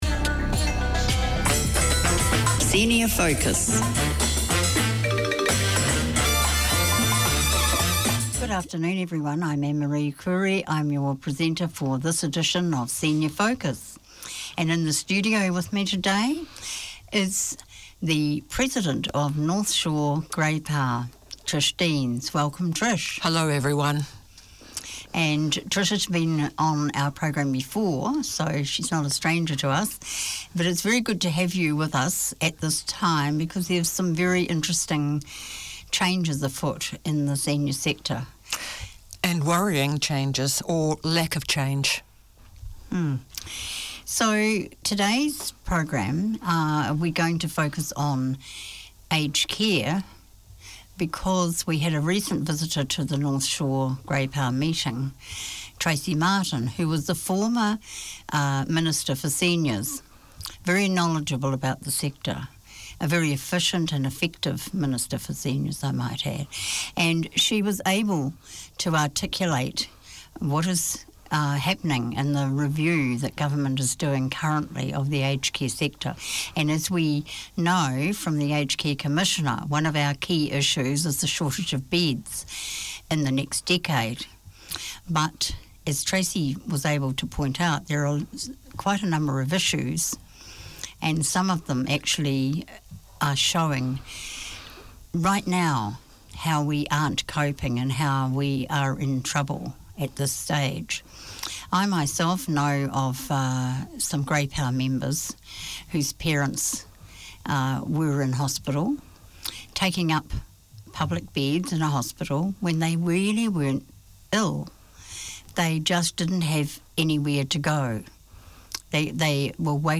Community Access Radio in your language - available for download five minutes after broadcast.
This half hour series features interviews and discussions on matters of interest to women in general and migrant women in particular. Women working in the community talk to women with shared experiences, to people who can help, to female achievers.